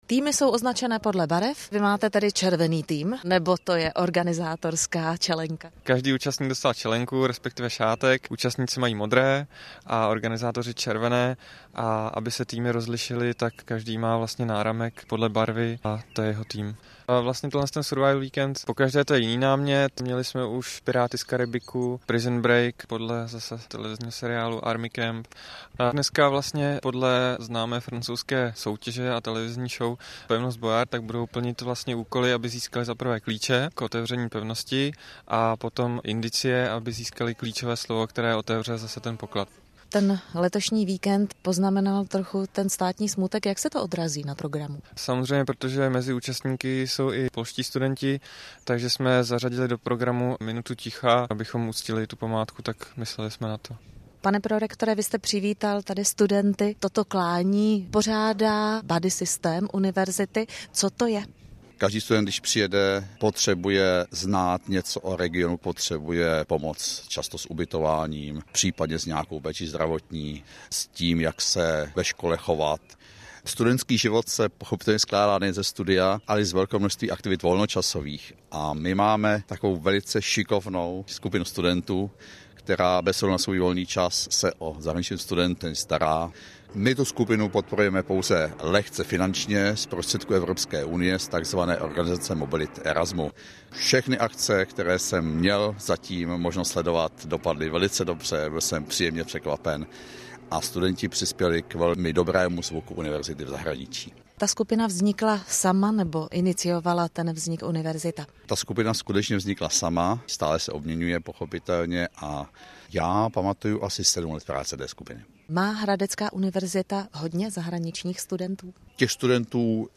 Reportáž v 2. příloze v rádiu proběhla v sobotu odpoledne.